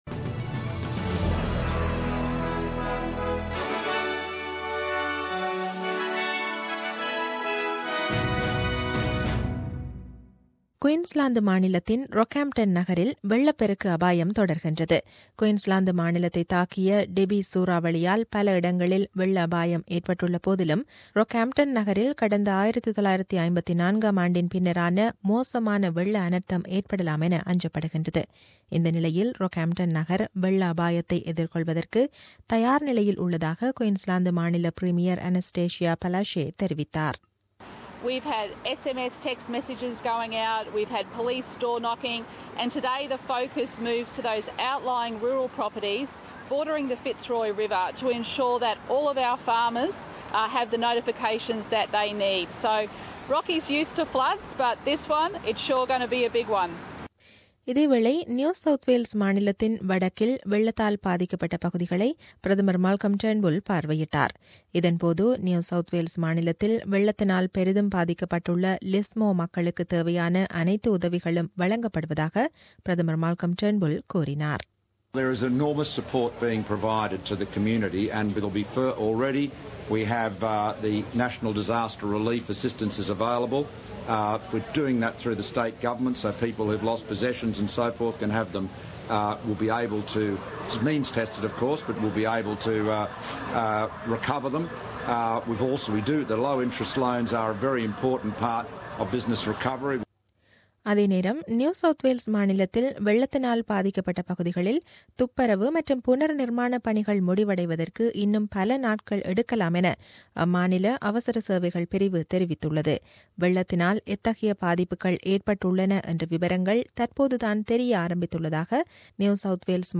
The news bulletin aired on 3 April 2017 at 8pm.